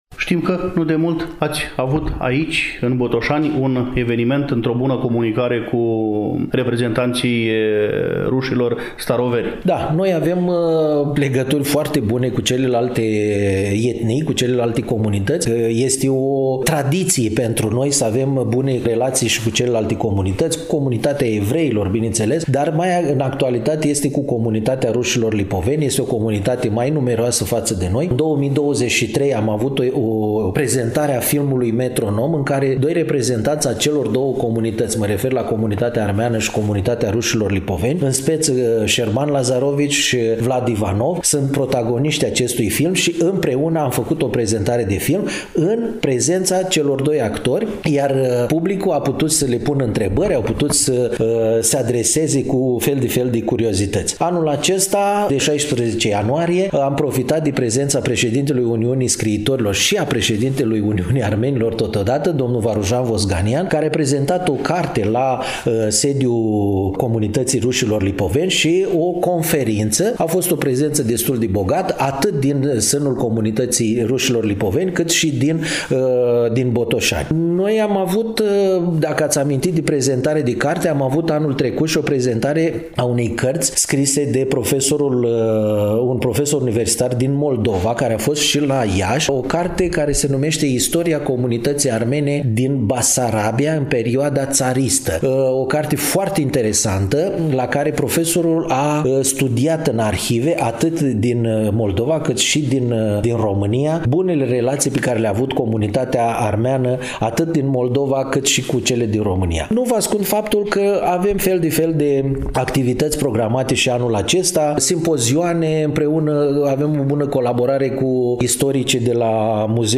În ediția de astăzi a emisiunii Dialog intercultural, relatăm din Comunitatea Armenilor din Municipiul Botoșani, situată pe Strada Armeană, Numărul 20.